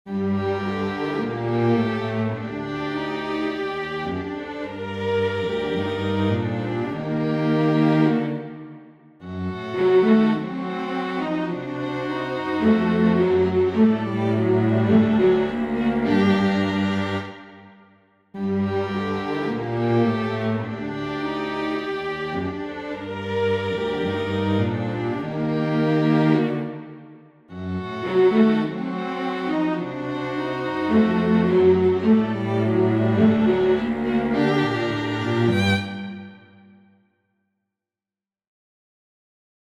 Here is the Challenge Melody): The instrumentation to be used for this challenge includes Vln. 1 & 2, Viola, and Cello. 4 Staves, with Vln. 1 already written for ya.